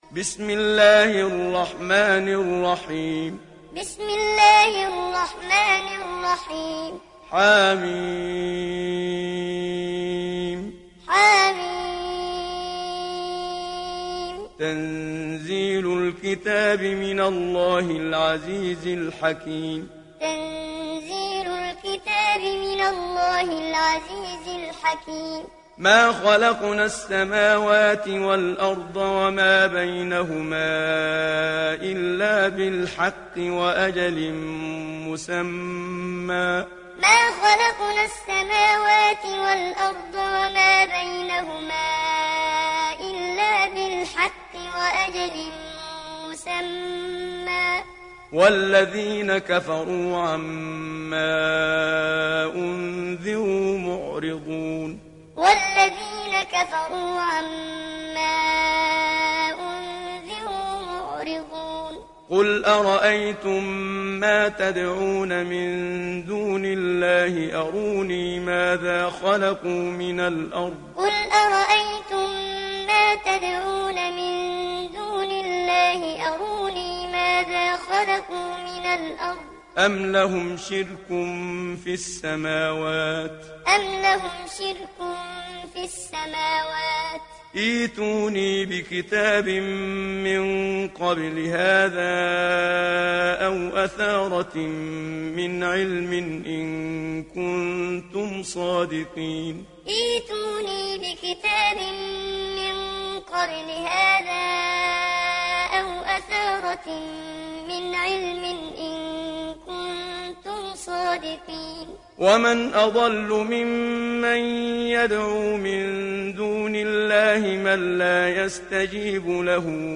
সূরা আল-আহক্বাফ ডাউনলোড mp3 Muhammad Siddiq Minshawi Muallim উপন্যাস Hafs থেকে Asim, ডাউনলোড করুন এবং কুরআন শুনুন mp3 সম্পূর্ণ সরাসরি লিঙ্ক